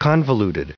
added pronounciation and merriam webster audio
172_convoluted.ogg